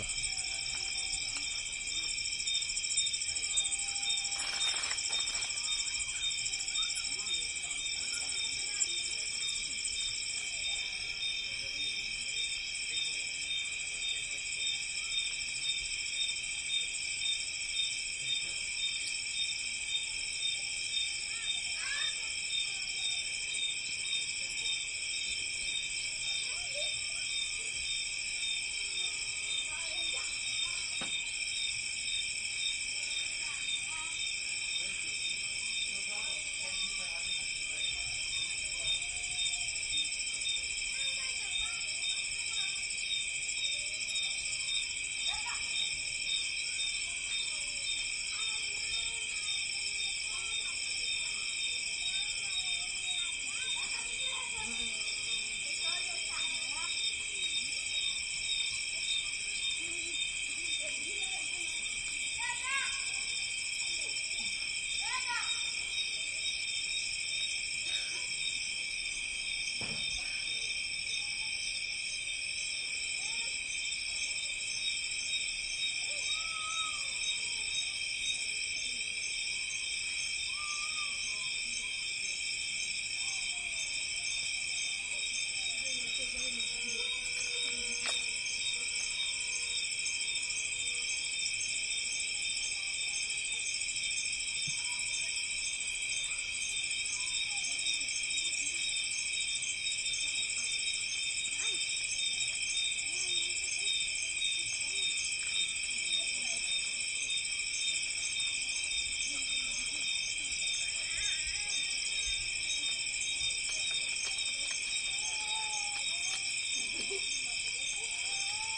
乌干达 " 蟋蟀夜村+鸟儿和远处的声音 普提，乌干达 MS
Tag: 乌干达 鸟类 蟋蟀 现场